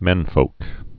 (mĕnfōk) or men·folks (-fōks)